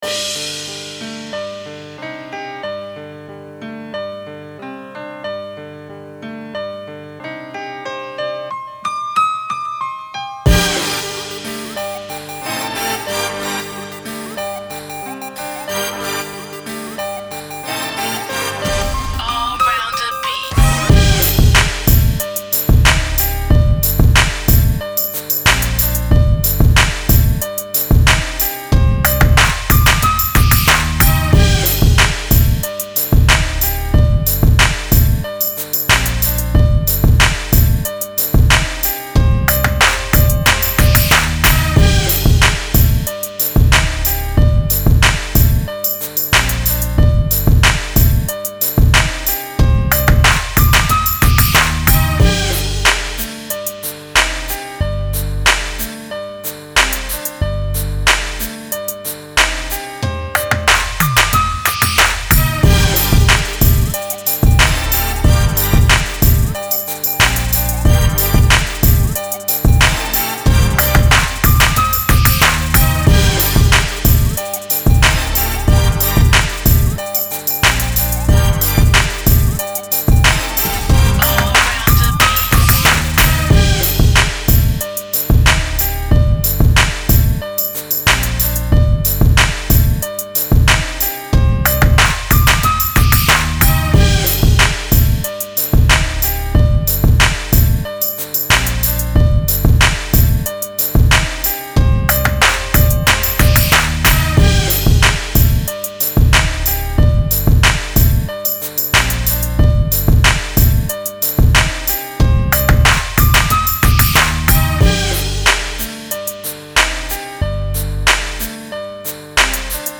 ловите лучшие 10 минусов, пряных, качающих, сочных.
минус 6